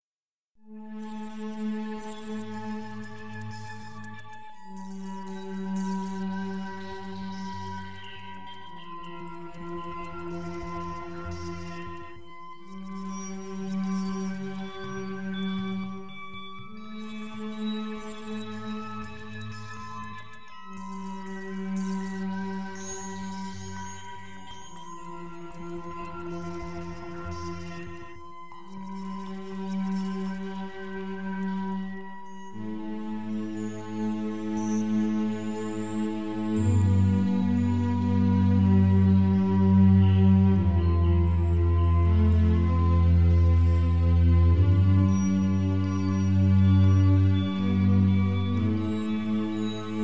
Wystarczy poczuć harmonię i wewnętrzne ciepło.